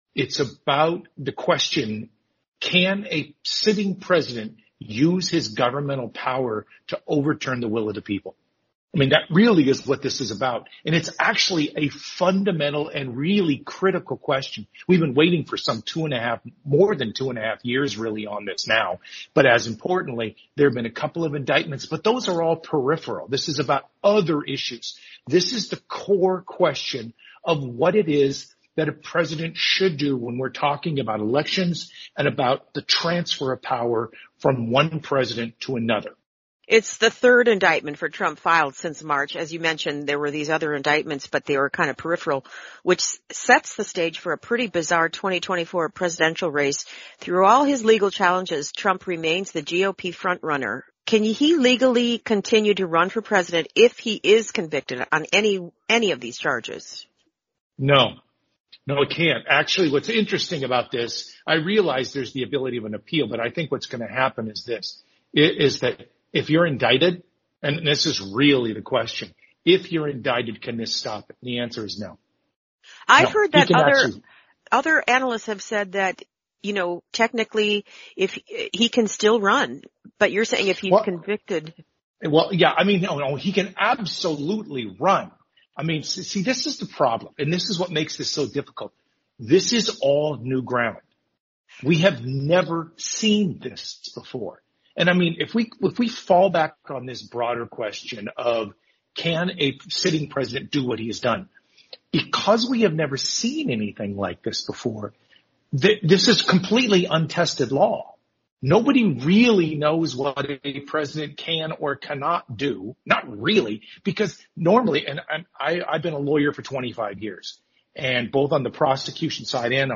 Africa News Tonight Clips